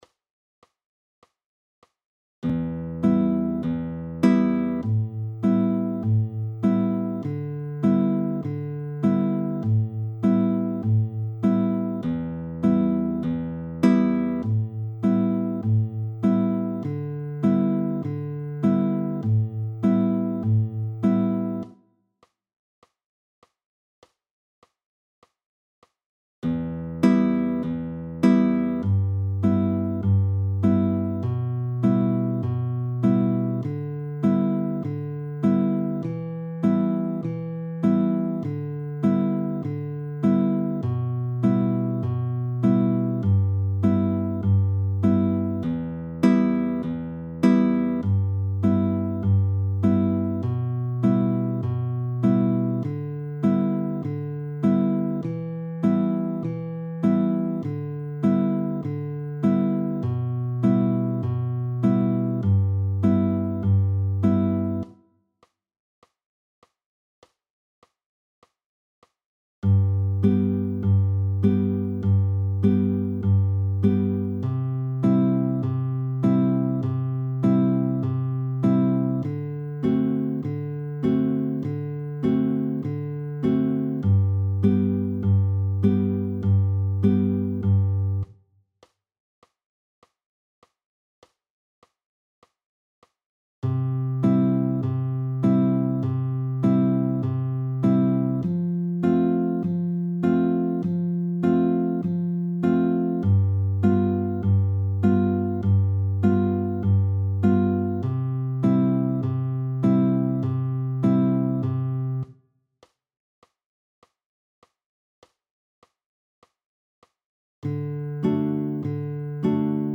I. Carter-Lick, nur Abschläge des Zeigefingers: PDF
Audio 100 bpm:
Carter-Lick-I..mp3